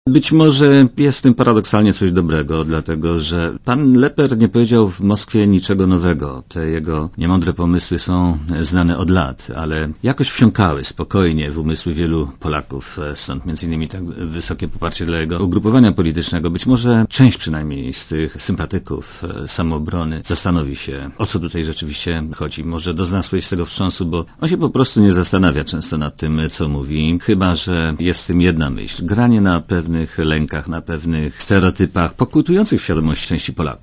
Mówi minister Cimoszewicz Lepper pojechał do Moskwy na zaproszenie narodowo-komunistycznego bloku Rodina.